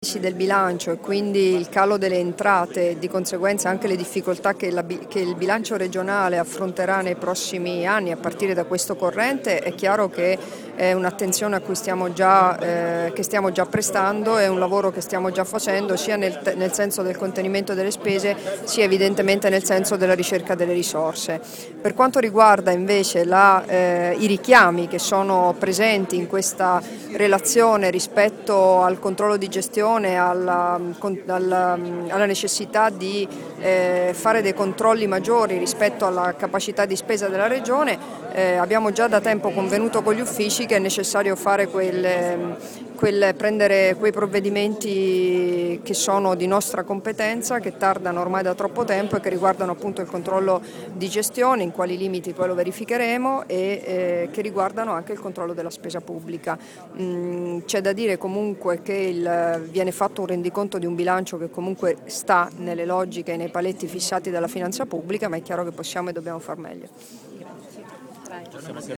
Ascolta le dichiarazioni di Debora Serracchiani - Formato MP3 [1657KB]
rilasciate in occasione del giudizio della Corte dei Conti di parificazione del Rendiconto generale della Regione FVG per l'esercizio 2012, a Trieste il 12 luglio 2013